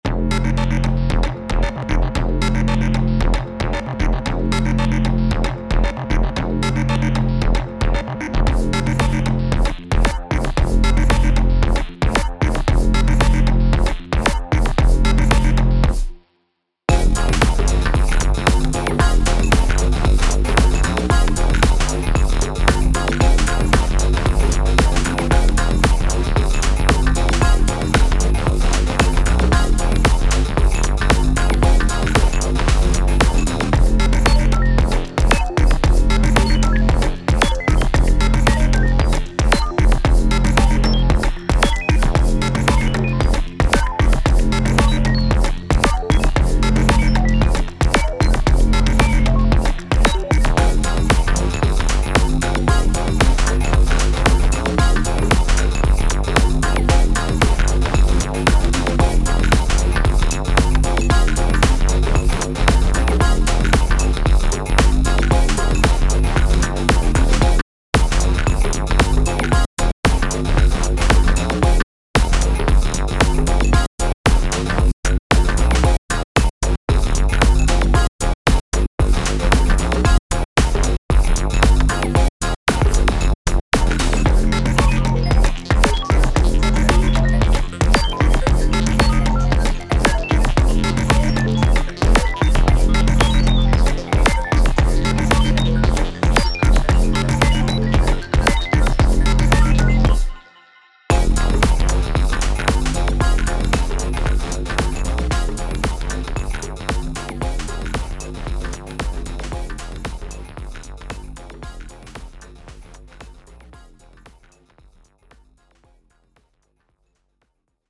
タグ: Beat EDM 不気味/奇妙 電子音楽 コメント: 不気味なロボット生産工場をイメージした楽曲。